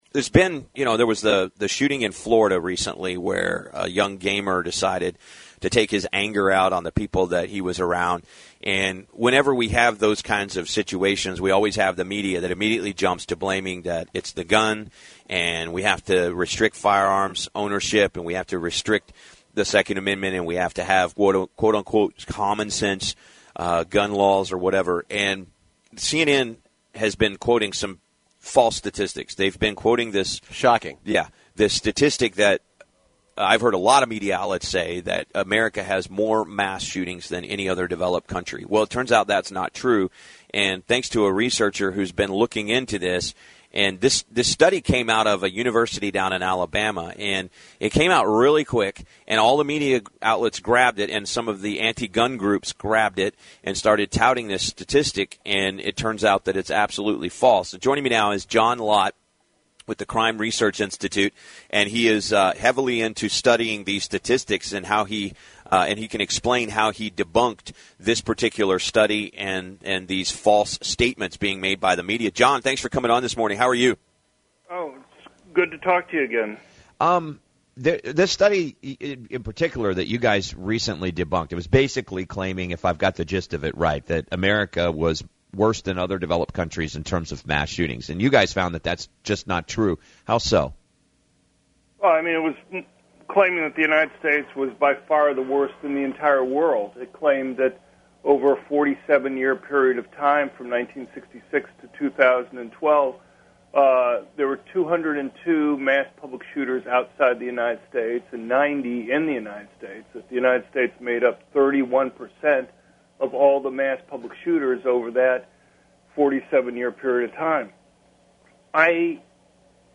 on the giant 50,000-watt WHAS-AM and also WLAP-AM to discuss our new research on mass public shooting rates in countries around the world